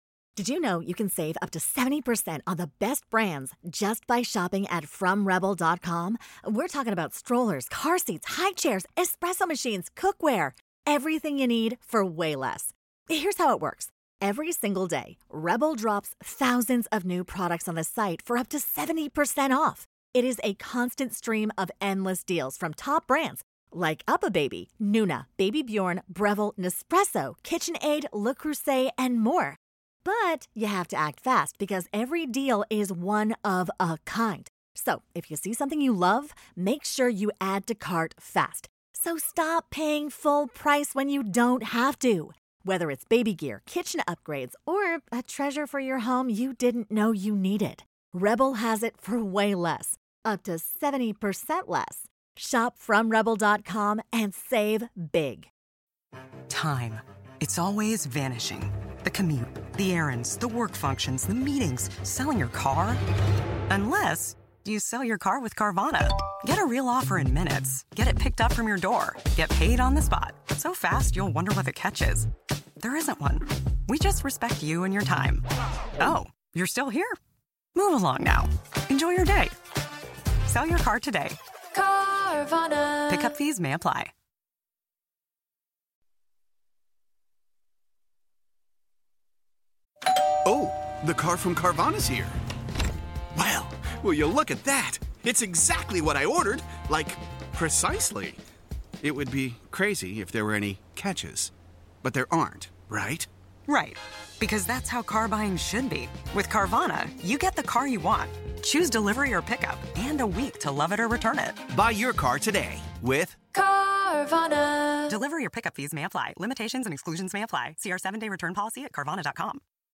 Stamford Superior Court in Connecticut